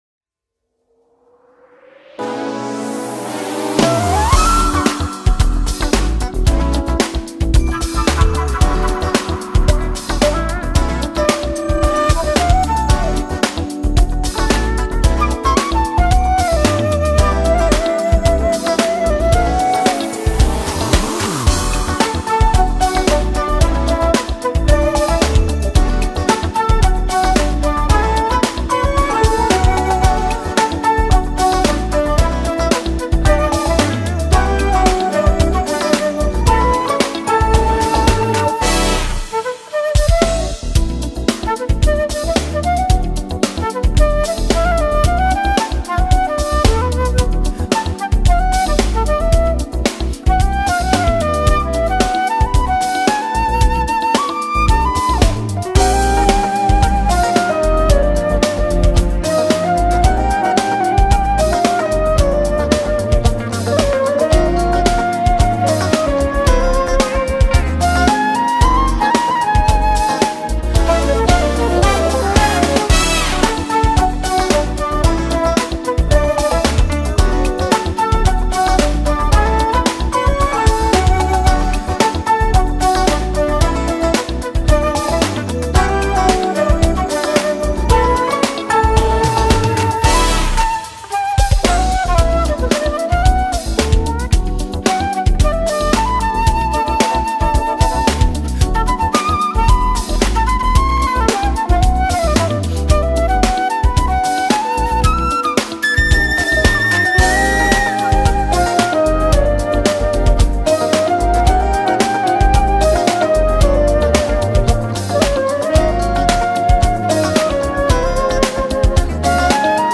jazz music for flute